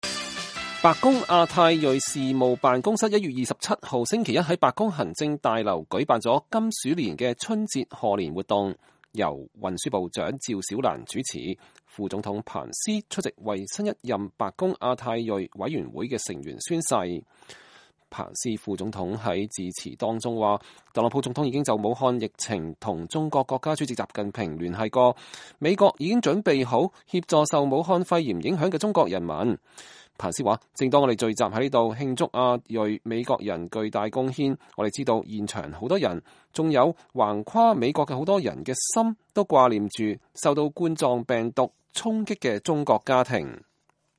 白宮亞太裔事務辦公室1月27號星期一在白宮行政大樓舉辦了金鼠年的春節賀年活動，由運輸部長趙小蘭主持，副總統彭斯（Mike Pence）出席為新一任白宮亞太裔委員會的成員宣誓。
彭斯副總統在演說當中特別強調，美國政府已經採取的防疫措施，要美國人民放心。